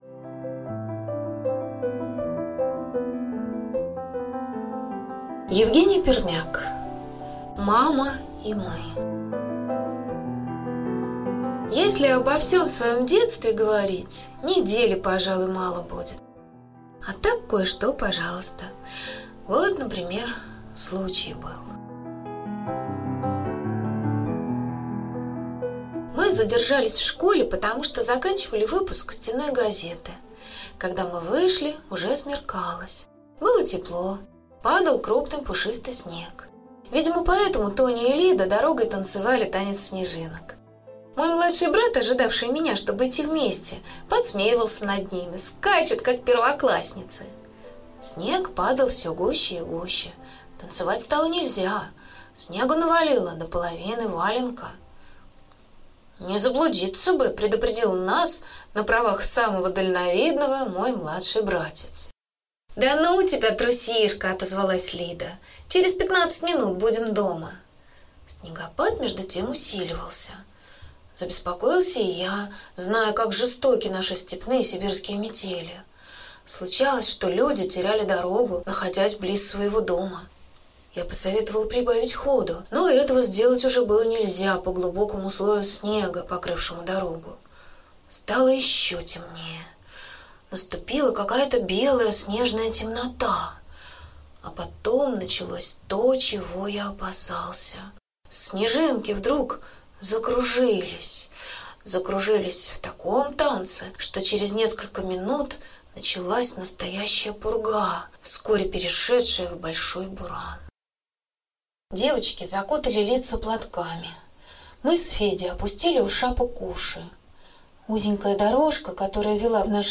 Мама и мы - аудио рассказ Пермяка - слушать онлайн